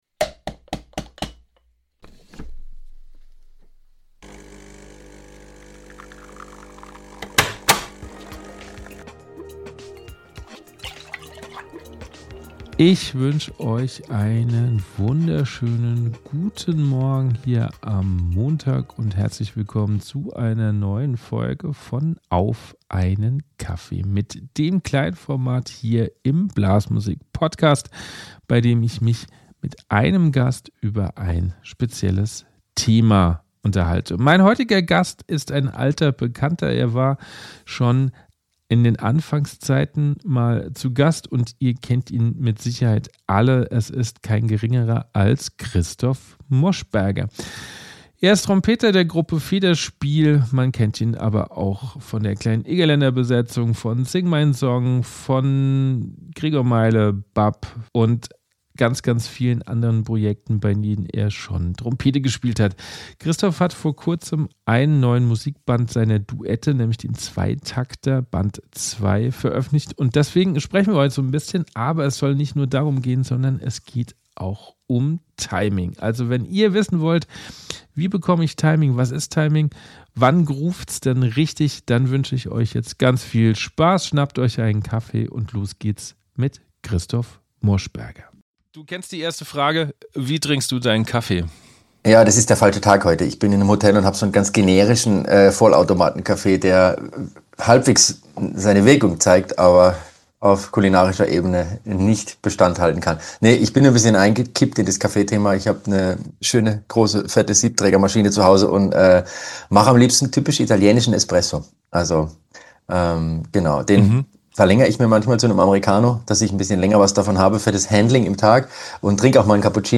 Er war zuletzt in der ersten Staffel zu Gast, deshalb haben wir uns auf einen Kaffee getroffen und ein wenig geplaudert.